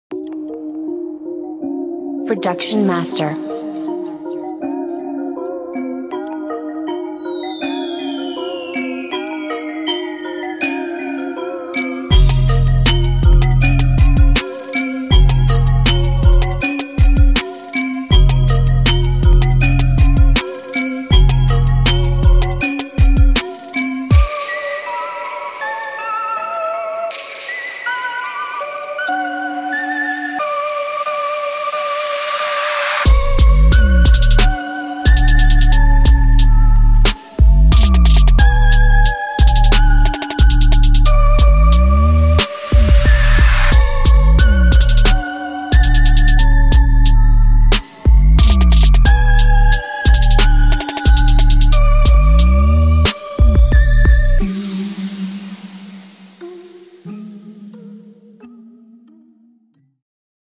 HEAVY BEATS & 808s
FIRE MELODIES & SYNTHS